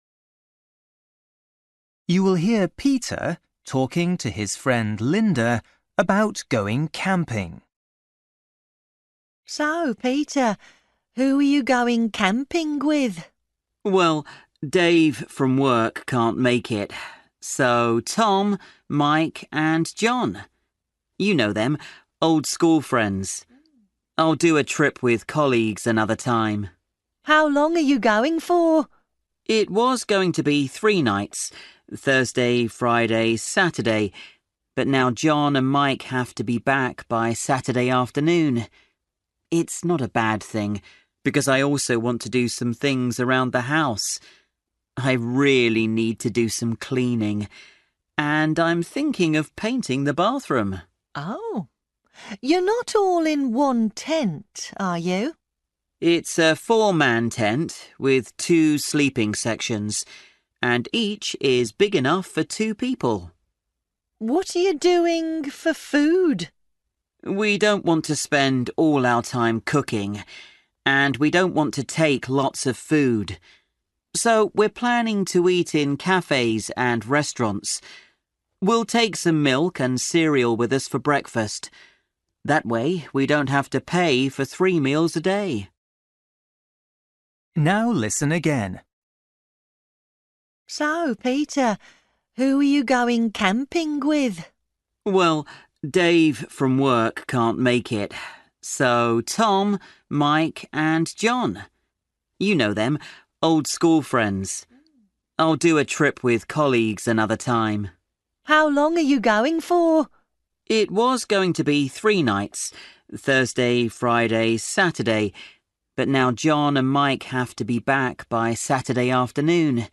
Bài tập trắc nghiệm luyện nghe tiếng Anh trình độ sơ trung cấp – Nghe một cuộc trò chuyện dài phần 9